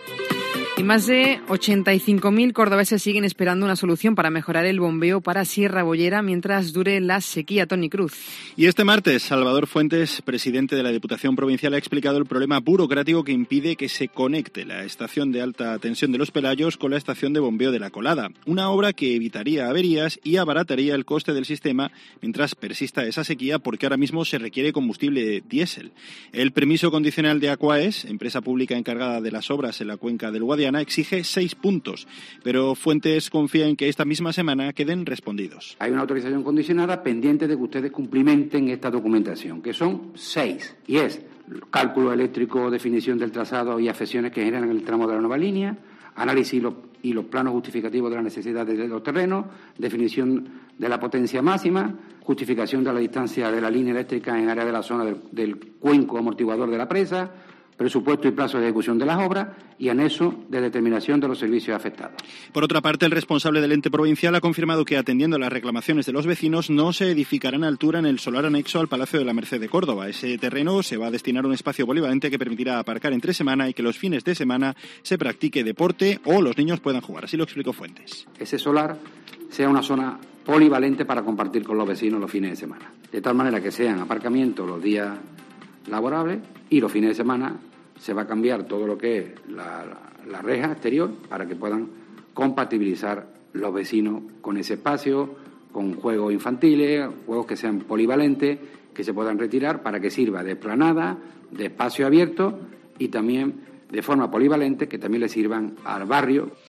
En este sentido y en rueda de prensa, Fuentes ha querido "dejar muy claro", mostrando un documento remitido por la sociedad estatal Aquaes y que la Diputación recibió el pasado "26 de diciembre", que él no quiere "levantar ningún tipo de polémica", pero que, lo cierto, es que lo que recibió la institución provincial el pasado mes fue una "autorización condicionada" respecto a su pretensión de usar la "línea de media tensión" de Los Pelayos para sustentar el bombeo de agua desde La Colada a Sierra Boyera, que hasta ahora se hace empleando gasóleo.